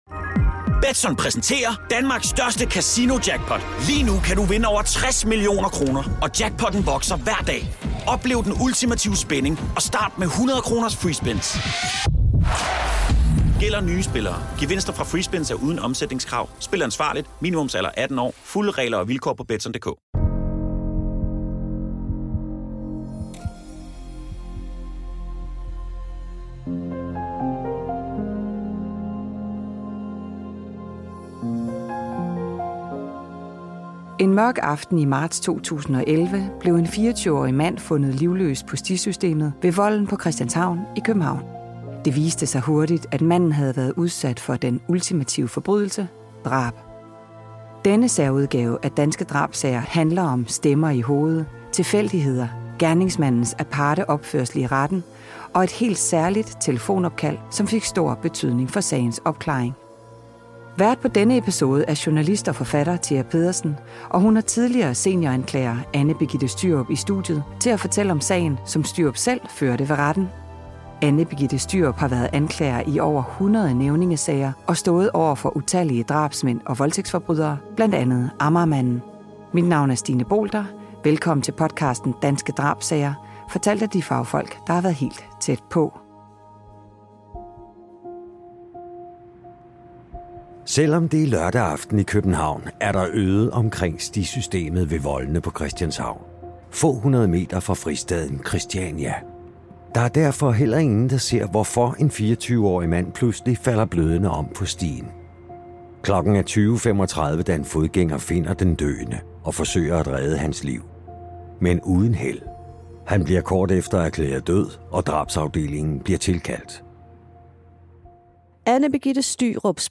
Medvirkende: Tidligere senioranklager